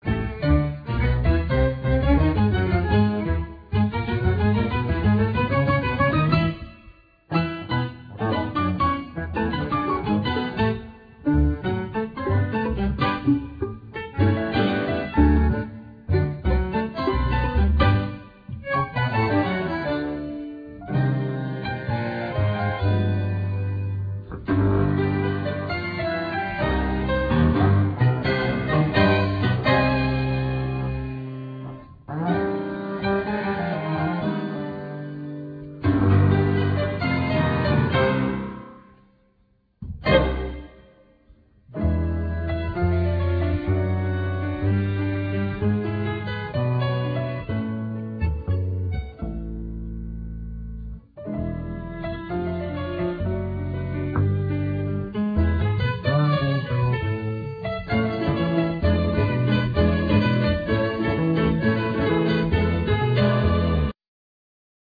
Piano
Bass
Drums
Bandneon
Violin
Sax